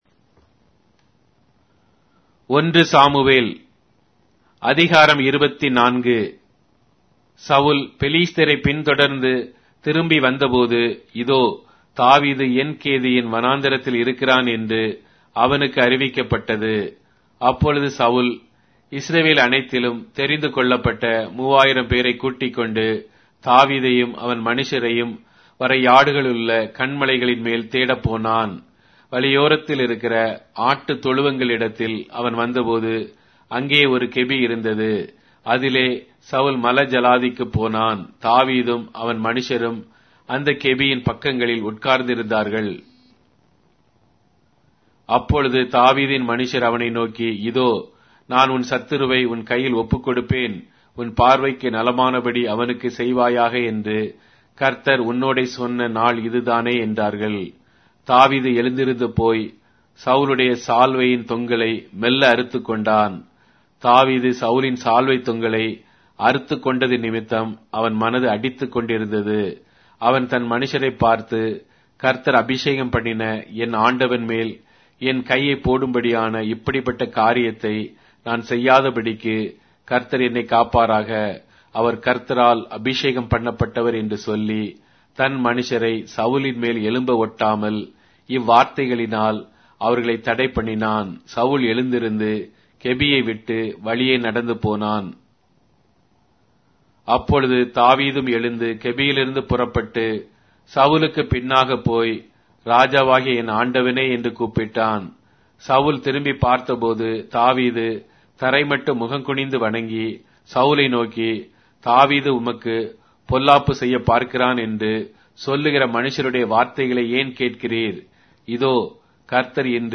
Tamil Audio Bible - 1-Samuel 17 in Litv bible version